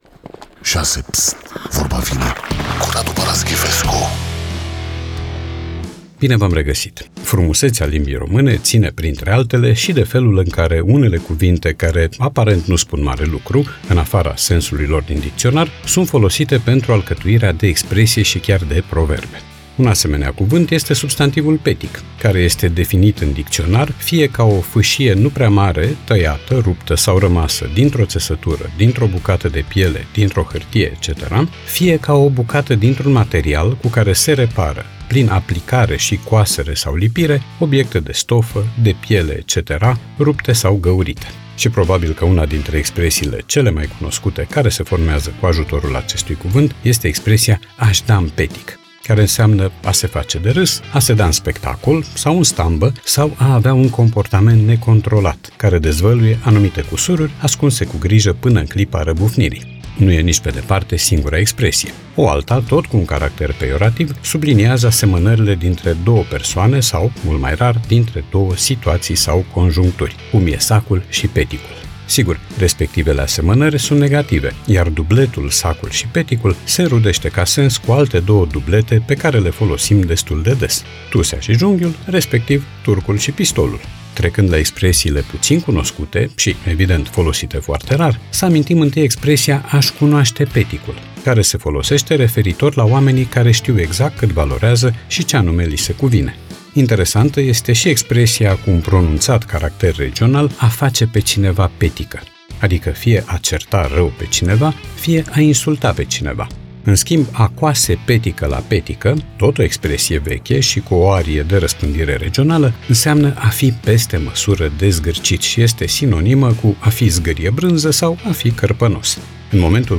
Podcast 16 septembrie 2025 Vezi podcast Vorba vine, cu Radu Paraschivescu Radu Paraschivescu iti prezinta "Vorba vine", la Rock FM.